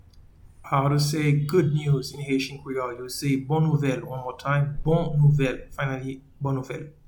Pronunciation:
Good-news-in-Haitian-Creole-Bon-nouvel.mp3